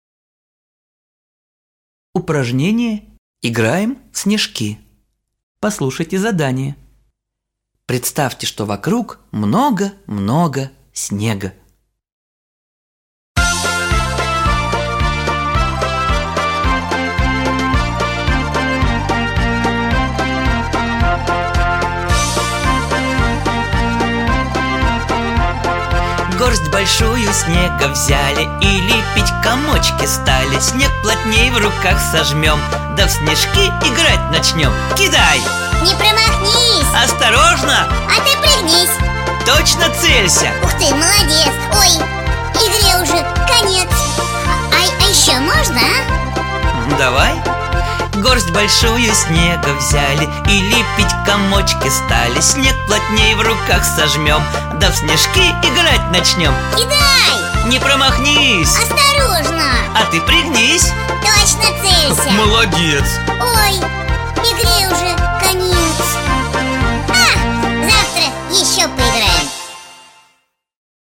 Детская песня. Играем в снежинки.